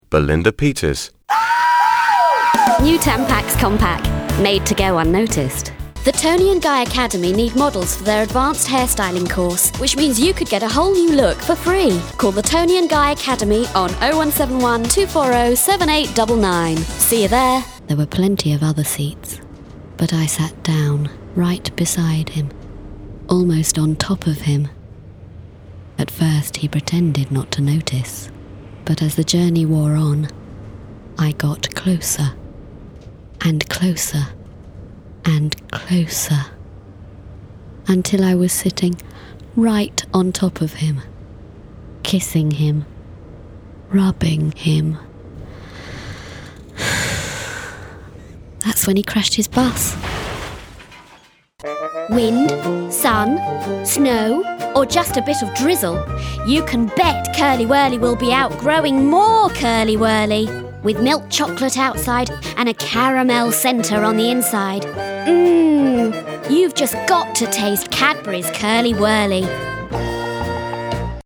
She is also at home with most regional accents.
She specialises in teenage and young voices and has a ‘deliciously older sexy read’!
• Female
• Standard English R P
• Standard U S